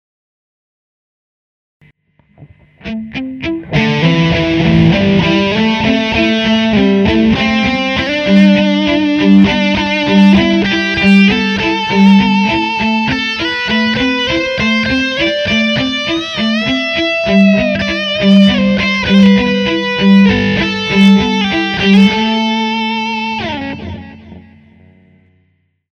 Skluzy na kytaře (slide)
Melodie vedená pomocí skluzů: